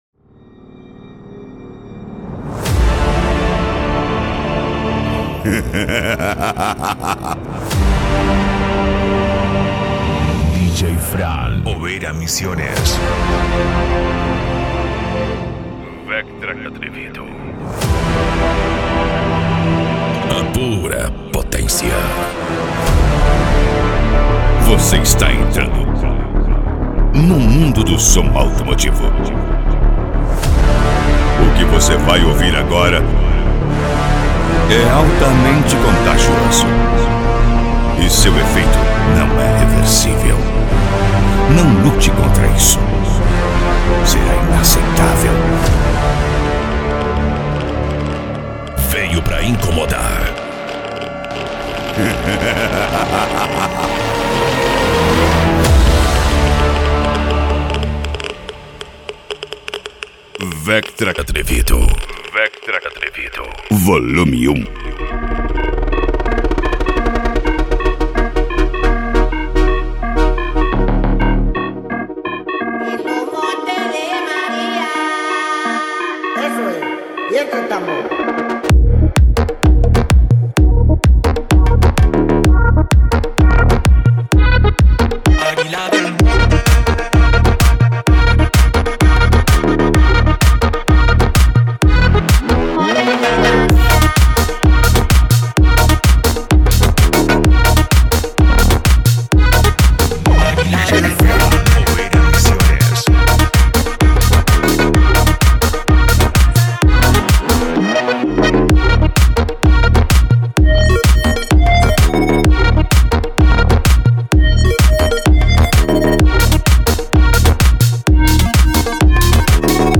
Bass
Cumbia
Musica Electronica
Hip Hop
Remix